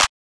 clap 30 (from my kit).wav